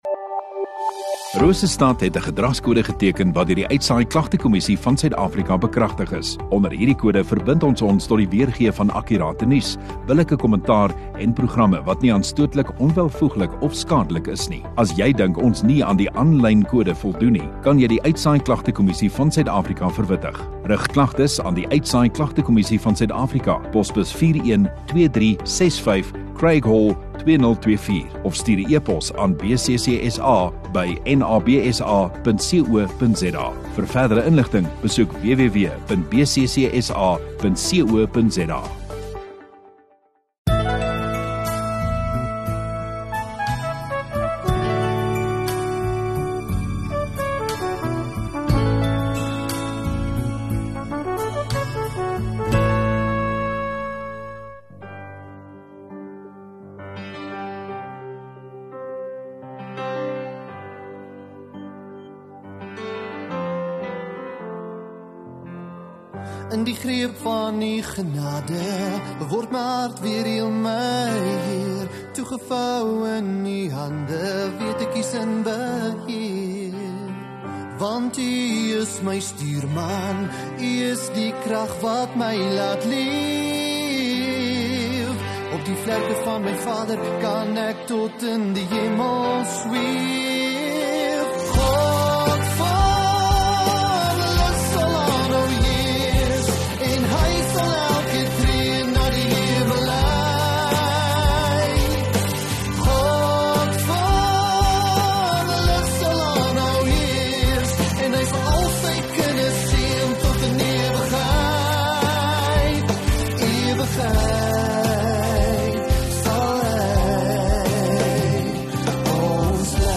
2 Jun Sondagaand Erediens